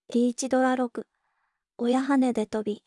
voicevox-voice-corpus / ROHAN-corpus /WhiteCUL_かなしい /ROHAN4600_0042.wav